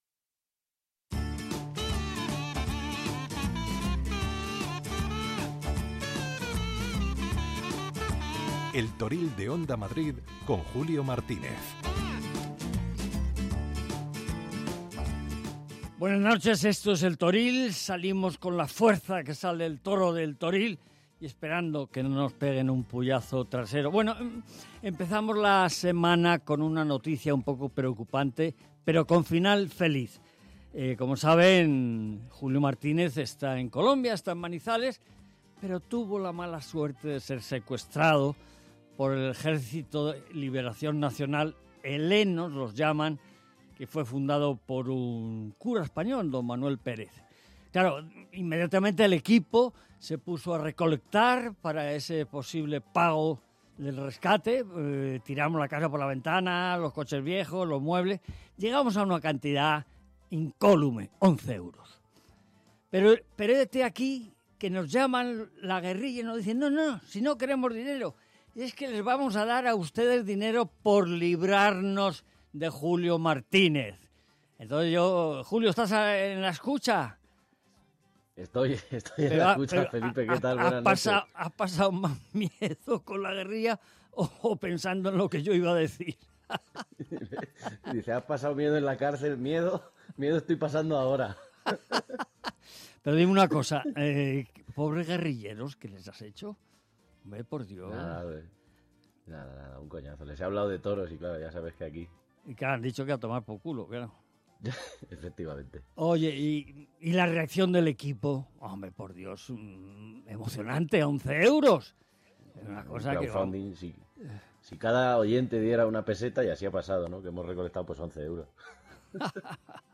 El Toril de Onda Madrid, el programa referente de la radio taurina.